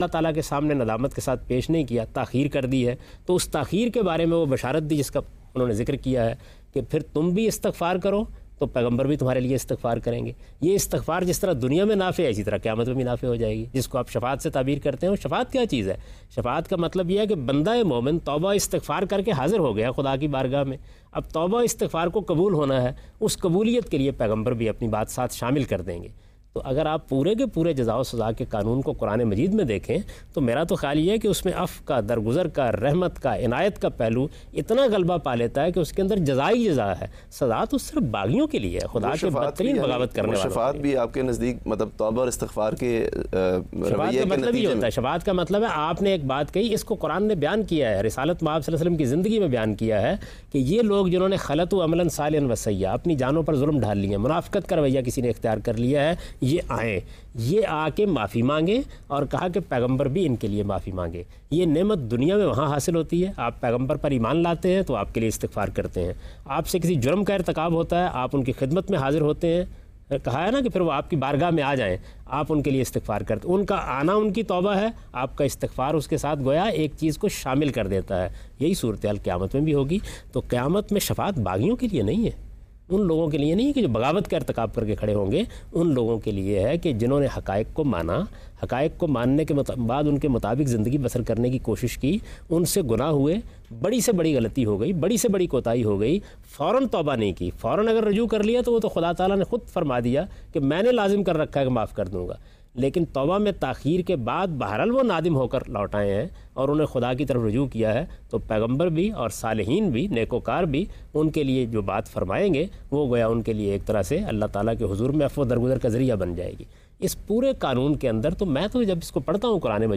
Category: TV Programs / Geo Tv / Ghamidi /
Questions and Answers on the topic “Why should there be Judgement Day?” by today’s youth and satisfying answers by Javed Ahmad Ghamidi.